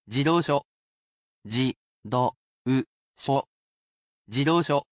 I read the word aloud for you, sounding out each mora.